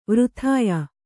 ♪ vřthāya